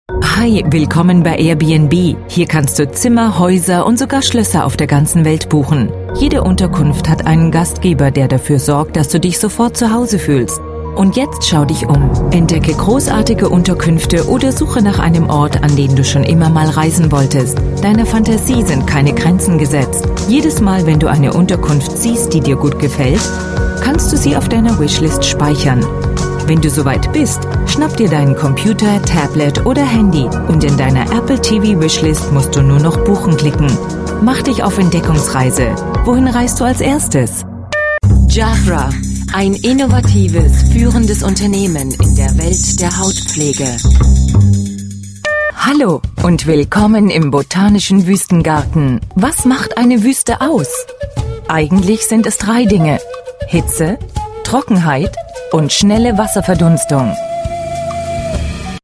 Almanca Seslendirme